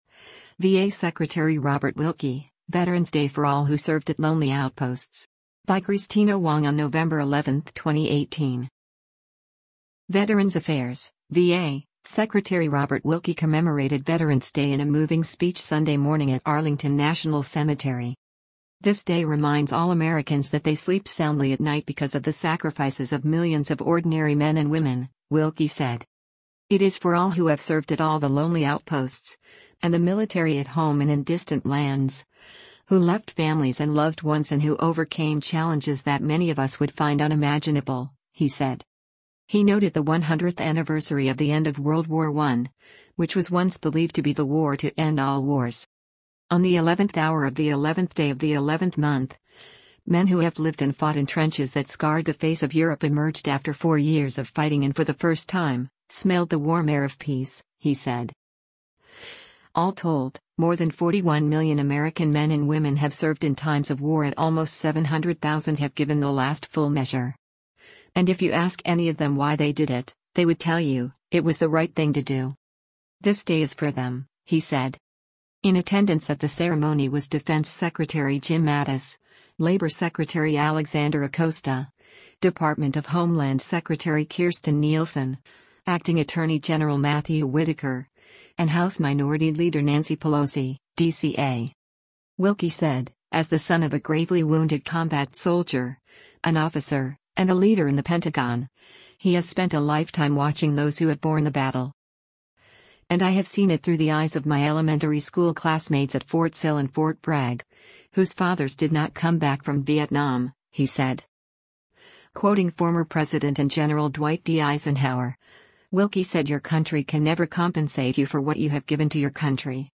Veterans Affairs (VA) Secretary Robert Wilkie commemorated Veterans Day in a moving speech Sunday morning at Arlington National Cemetery.